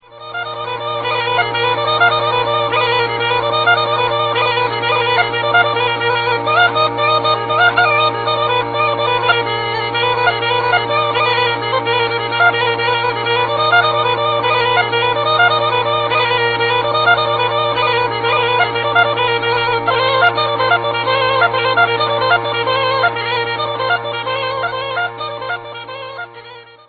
and sheer finger-flying bravura of